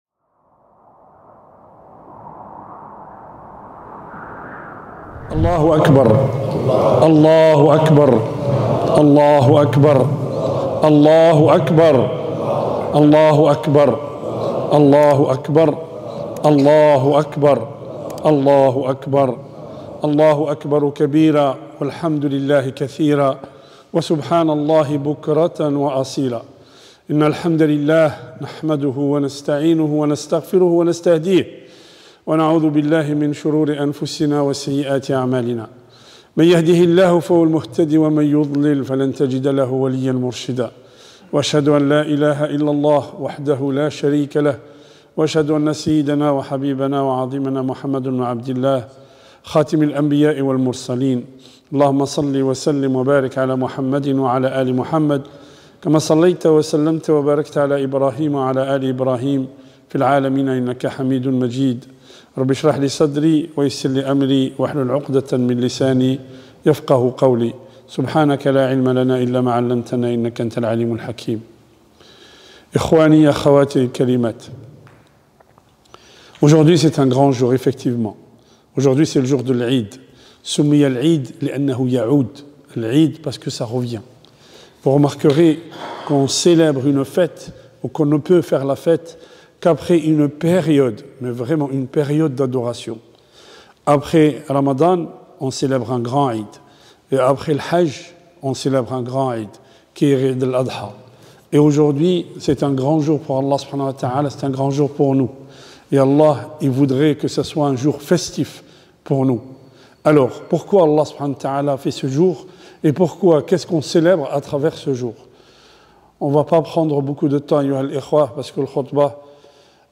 Khutba de l'Aid El Adha 2023.mp3